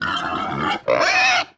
Index of /App/sound/monster/wild_boar_god
attack_2.wav